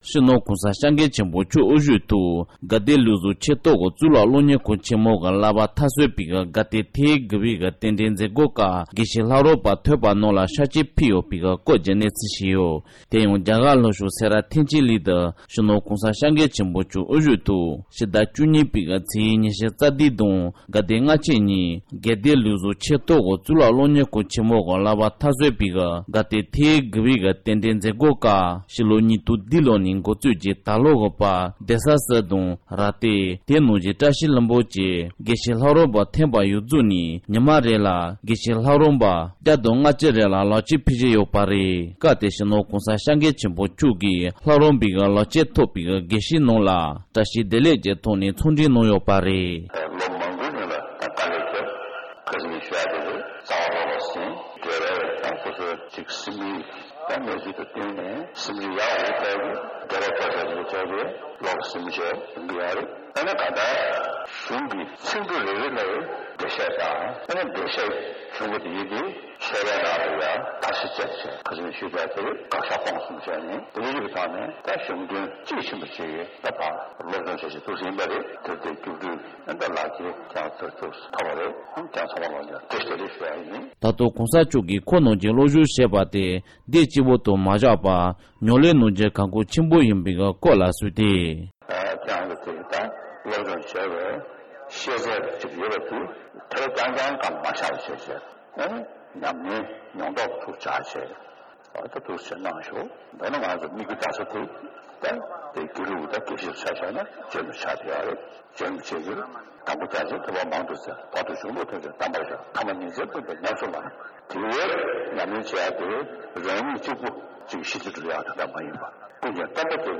ས་གནས་ནས་བཏང་བའི་གནས་ཚུལ་ལ་གསན་རོགས༎